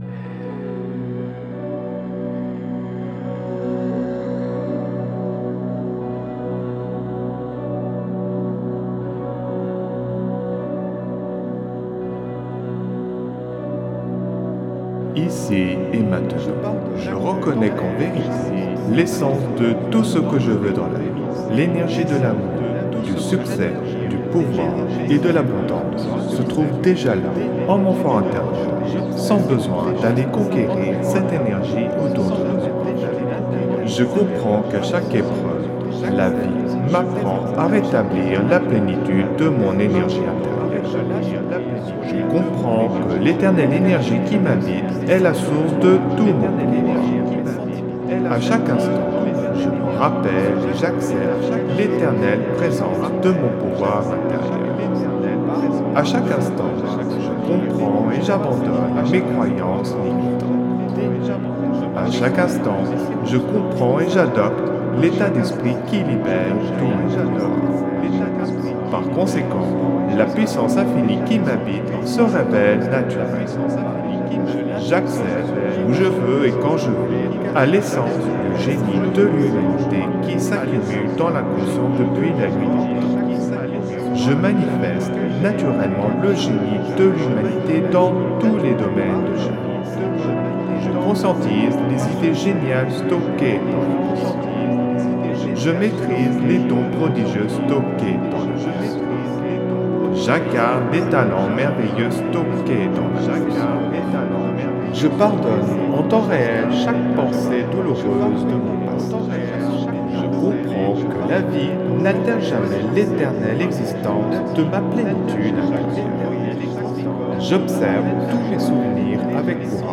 (Version ÉCHO-GUIDÉE)
Alliage ingénieux de sons et fréquences curatives, très bénéfiques pour le cerveau.
Pures ondes gamma intenses 61,53 Hz de qualité supérieure. Puissant effet 3D subliminal écho-guidé.
SAMPLE-Pouvoir-1-Blessures-emotionnelles-echo-guide.mp3